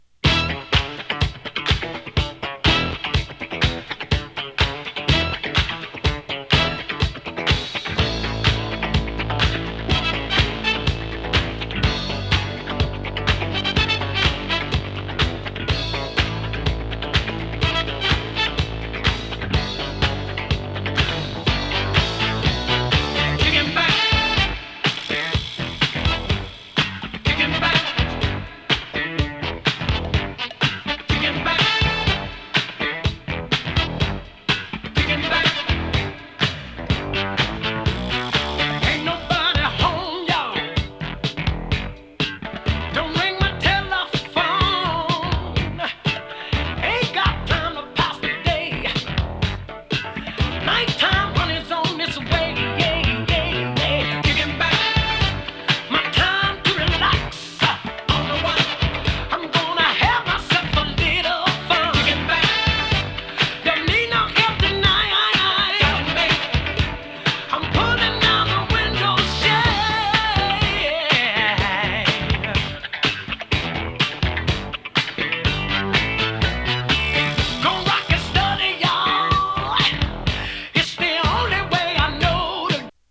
パワフルに前進していくようなディスコ・ファンク！
強靭ファンク・サウンドを下敷きにしたグルーヴと迫力満点のヴォーカルがマッチしたディスコ・クラシック。